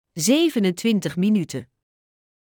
Alle functies van deze airfryer worden in het Nederlands uitgesproken met een duidelijke vrouwenstem.
27.-TWENTY-SEVEN-MINUTES.mp3